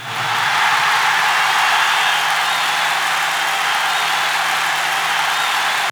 Crowd (3).wav